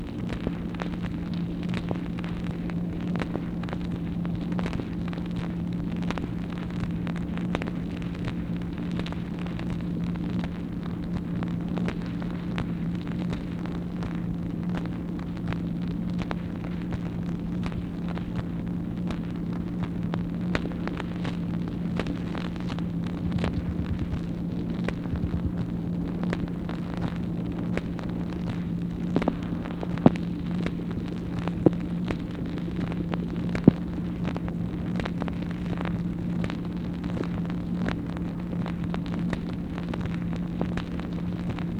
MACHINE NOISE, April 8, 1964
Secret White House Tapes